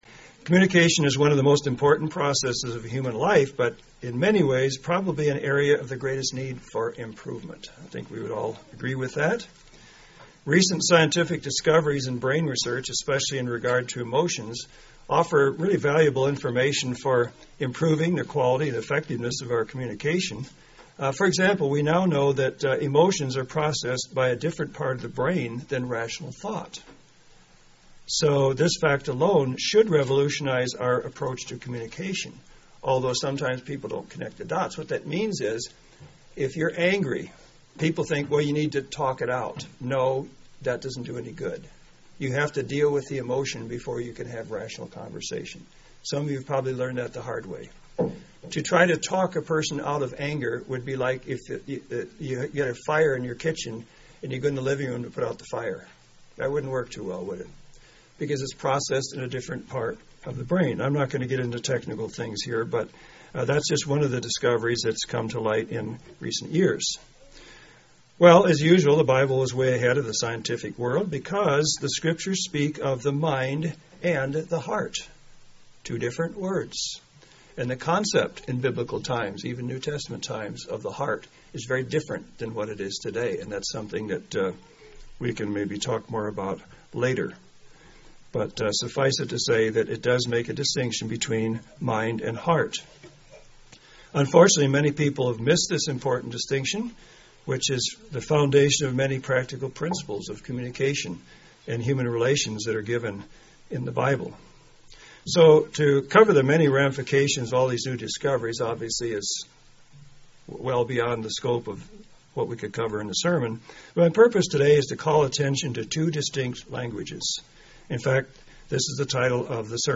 UCG Sermon Notes Head-to-Head and Heart-to-Heart Bend 2-15-14 Introduction – Communication is one of the most important processes of human life yet in many ways an area of the greatest need for improvement.